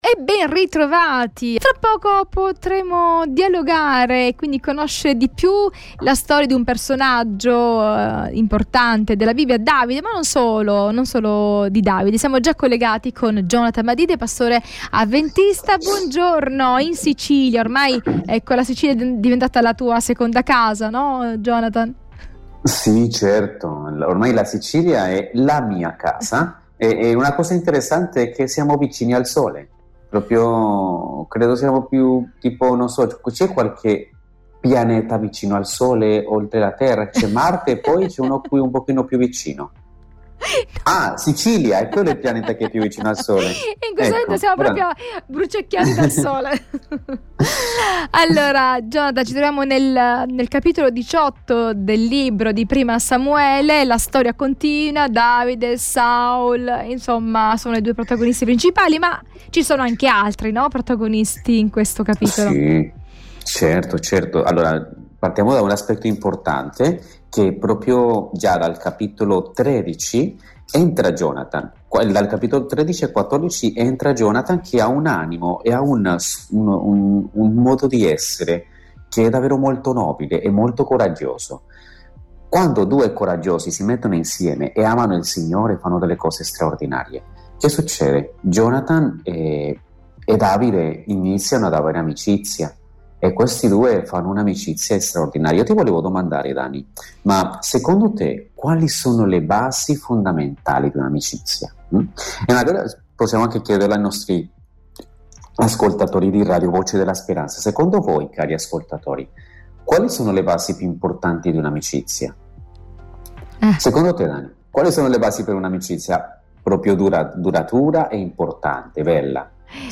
dialogo
pastore avventista